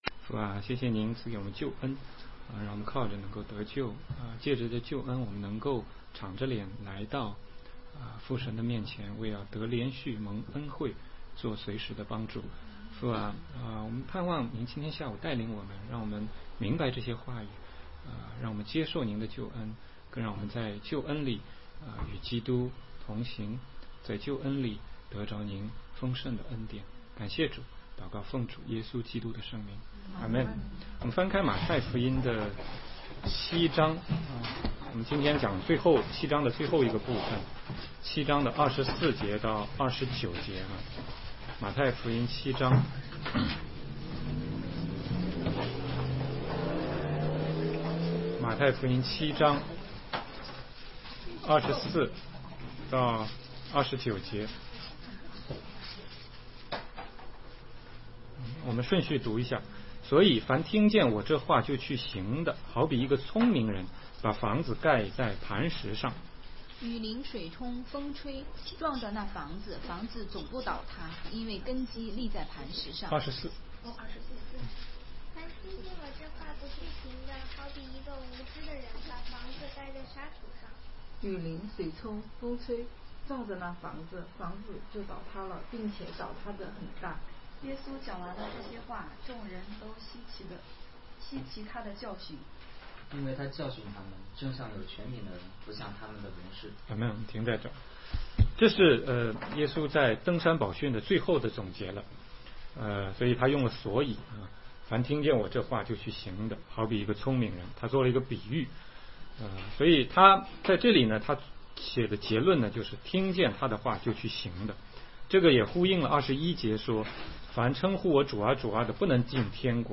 16街讲道录音 - 马太福音7章24-29节